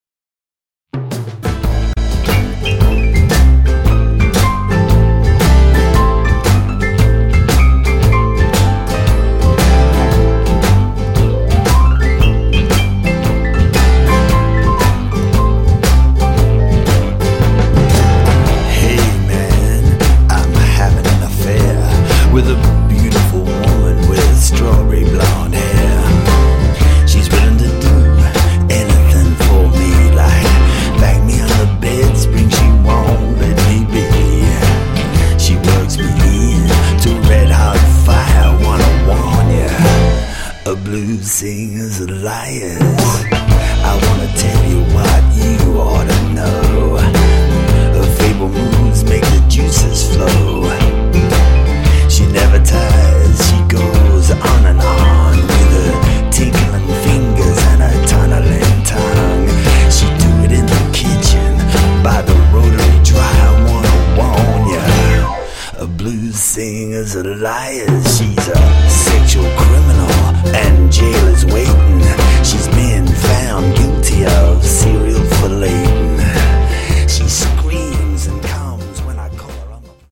bass
Keys
vocals, guitar.